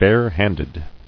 [bare·hand·ed]